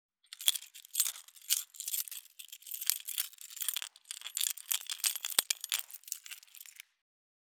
168.鍵【無料効果音】